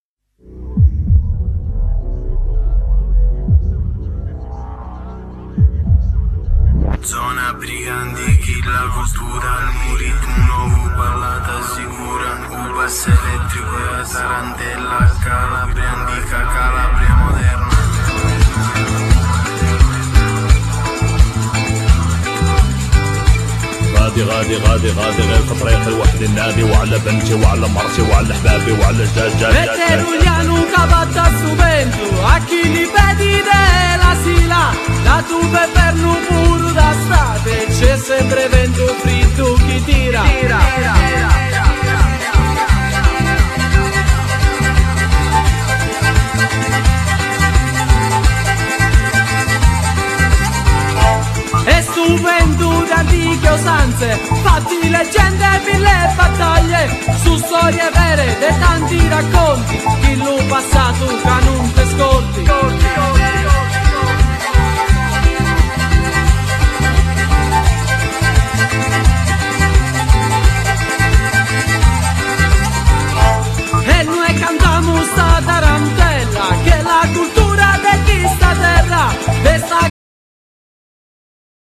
Genere : Folk rock